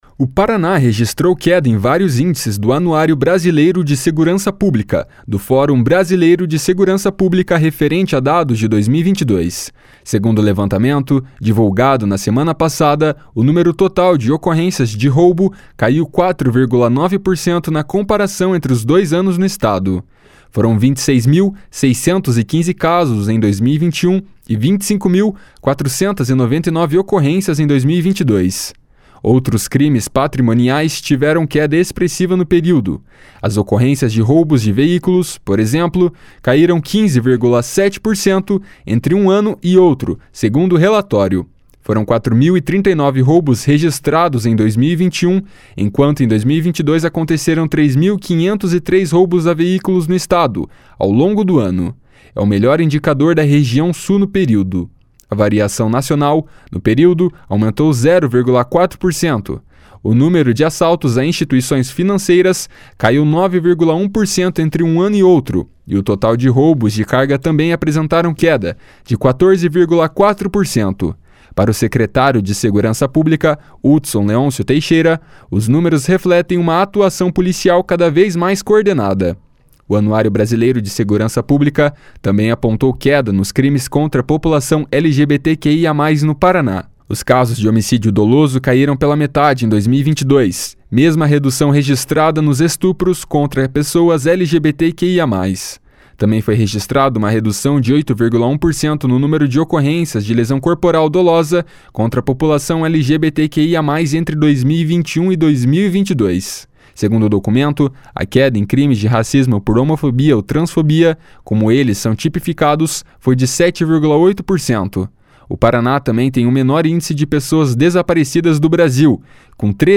Para o secretário de Segurança Pública, Hudson Leôncio Teixeira, os números refletem uma atuação policial cada vez mais coordenada.